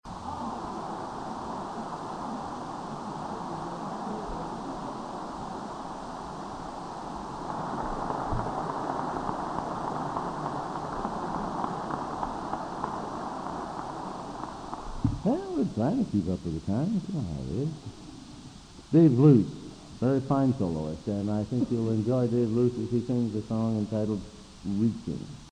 Collection: Broadway Methodist, 1982